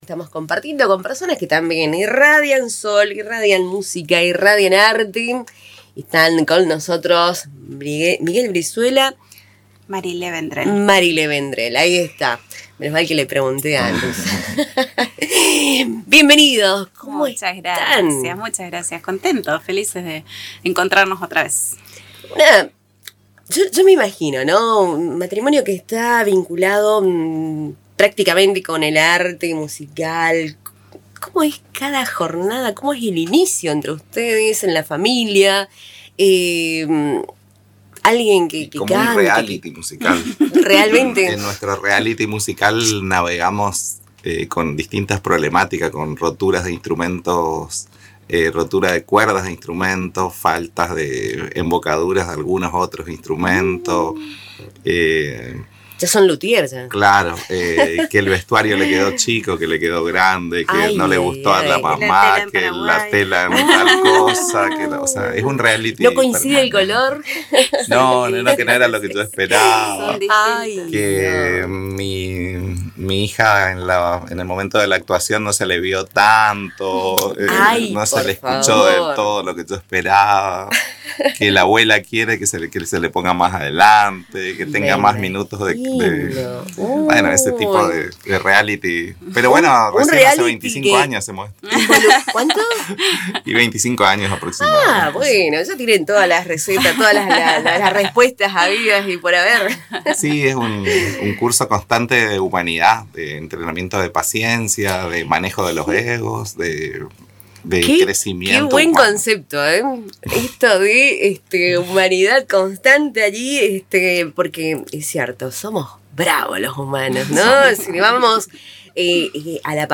visitaron Radio Tupambaé y destacaron que educar en arte va más allá de la técnica: implica paciencia, tolerancia y colaboración.